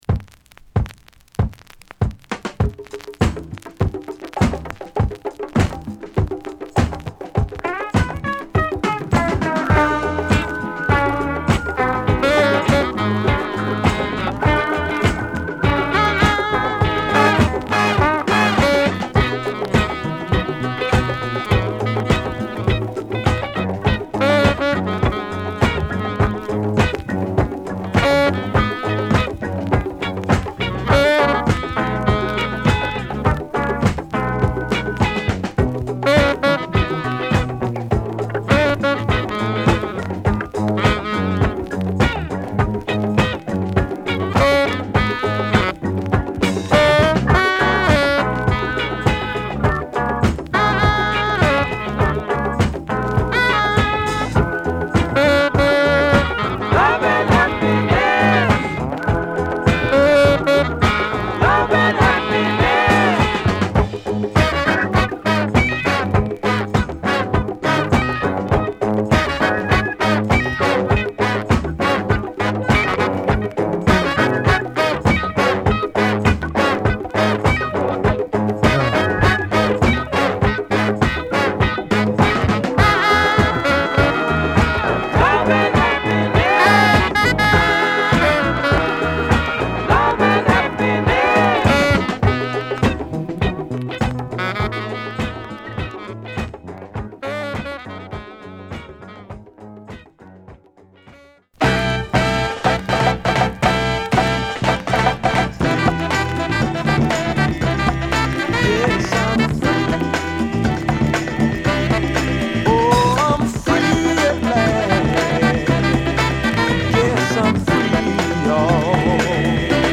Rare Afro Jazz Funk Classics！！